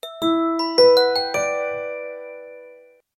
Рингтоны без слов , Рингтоны на смс и уведомления
Короткие рингтоны